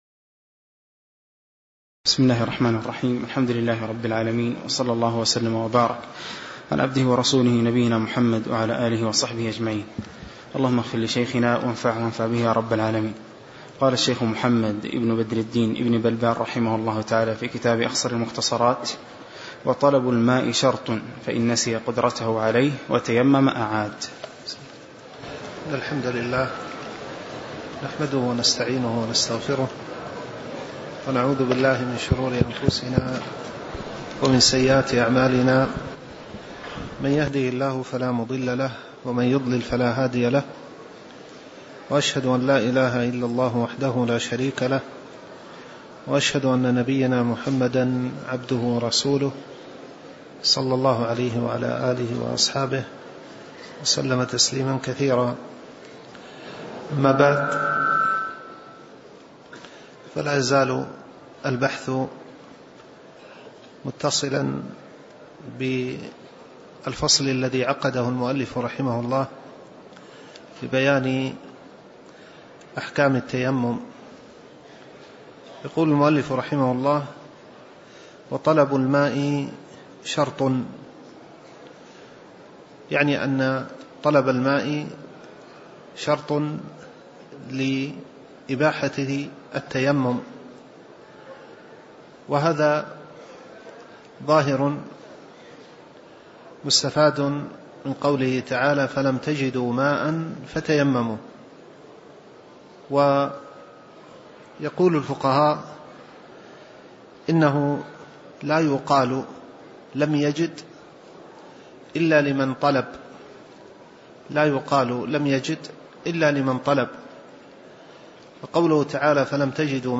تاريخ النشر ١٧ ربيع الأول ١٤٣٩ هـ المكان: المسجد النبوي الشيخ